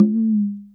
SingleHit_QAS10785.wav